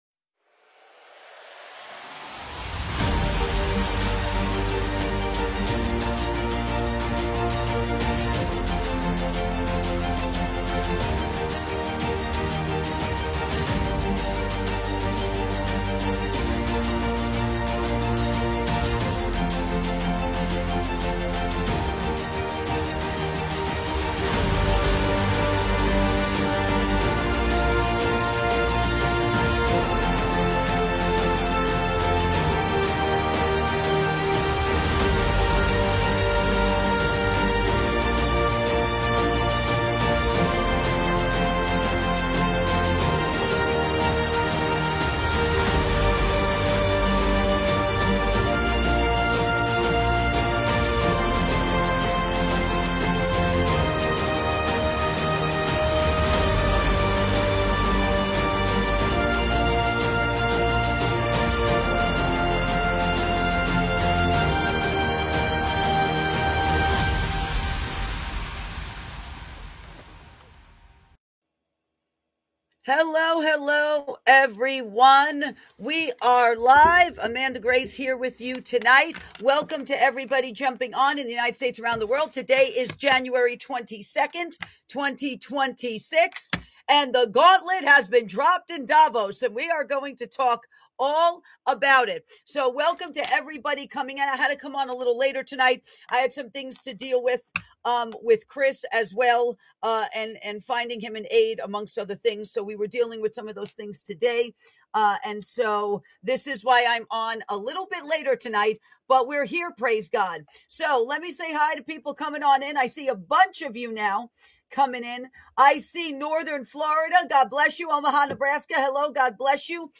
Talk Show Episode
Talk Show